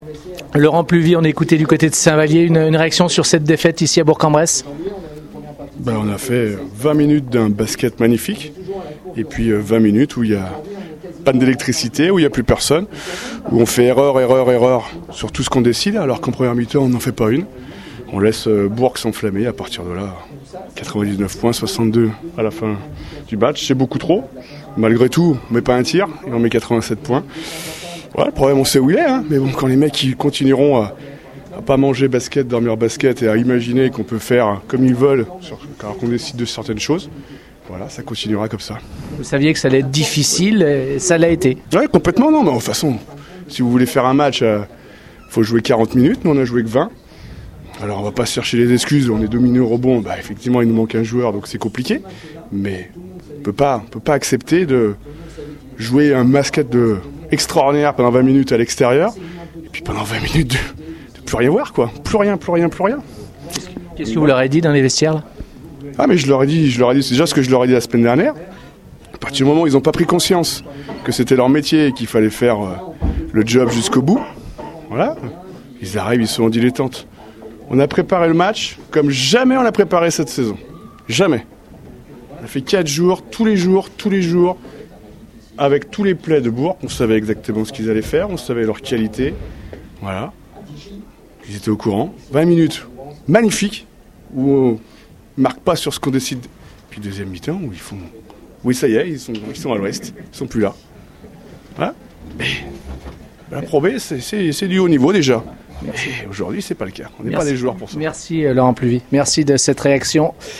coach Saint-Vallier